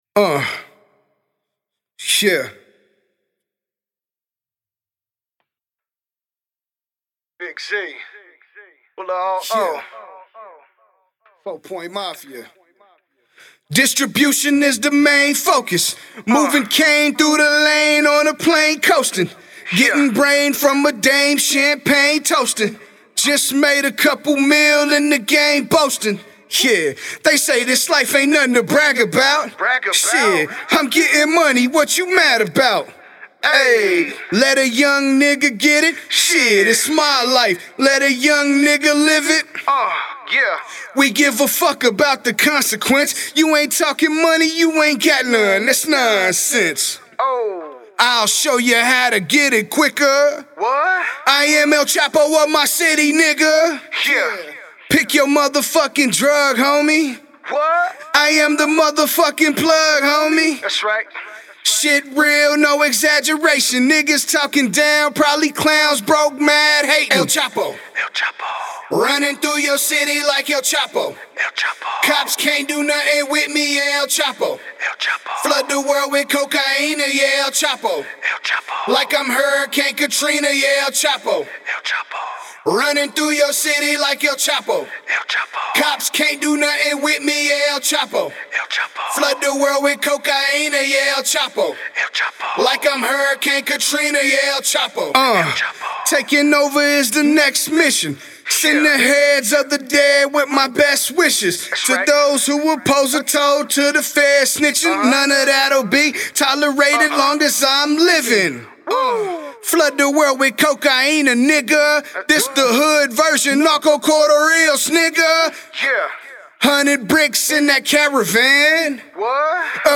Real Gangster Hip-Hop Music!